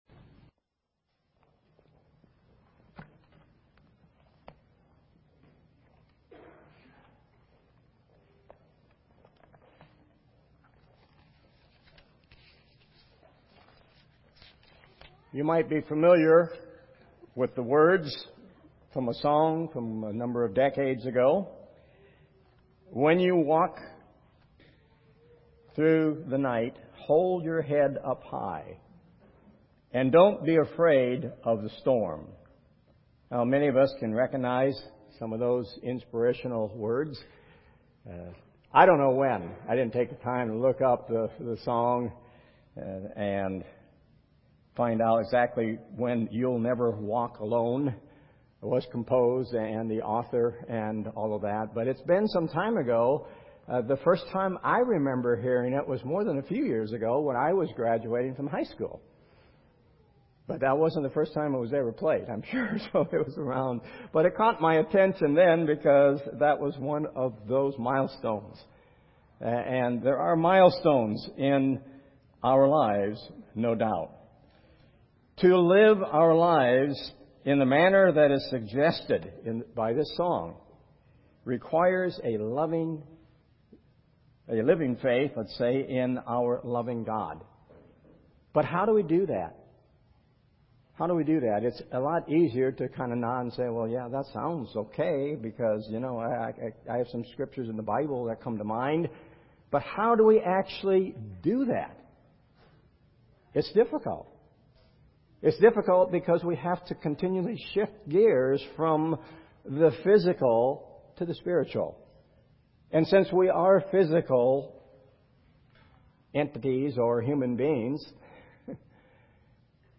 In part 3 of our sermon series on the topic of faith, let’s examine this passage more closely.